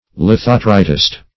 Lithotritist \Li*thot"ri*tist\, n.